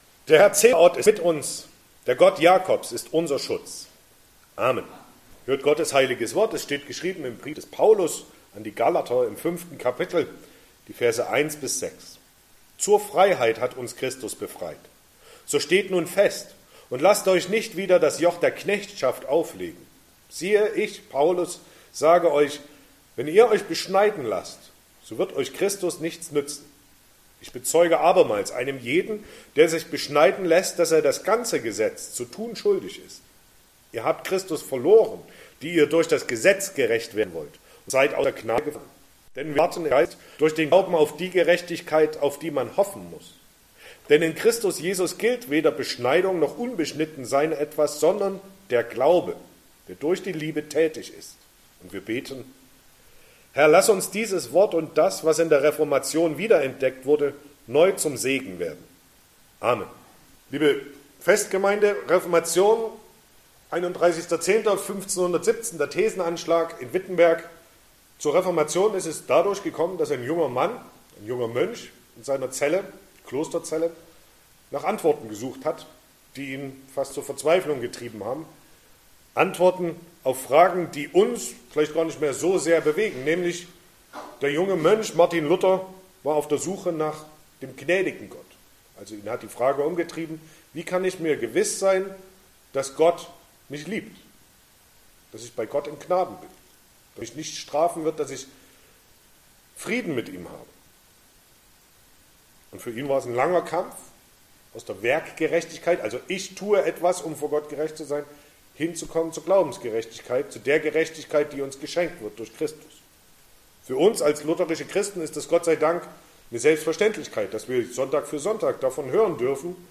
Reformationstag Passage: Galater 5, 1-6 Verkündigungsart: Predigt « 20.